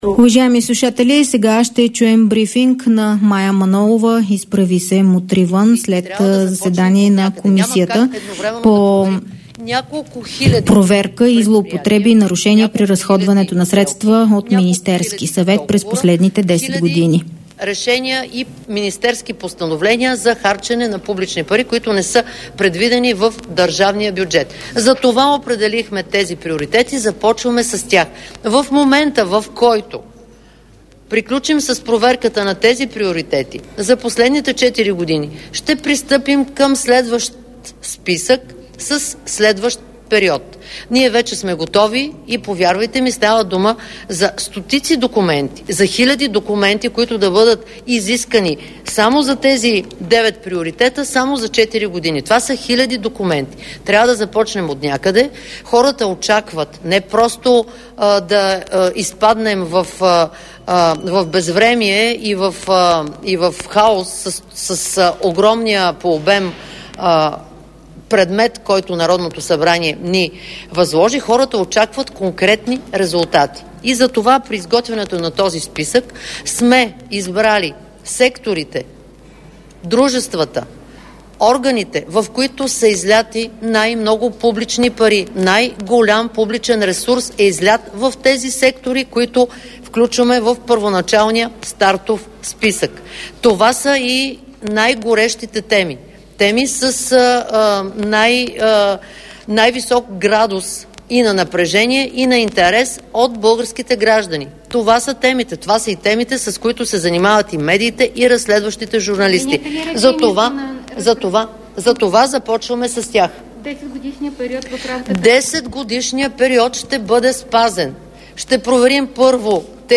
3. Разни. - директно от мястото на събитието (пл. „Княз Александър I" №1, зала 134)
Директно от мястото на събитието